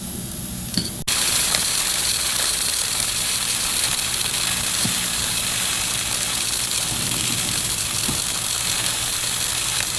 Короткое замыкание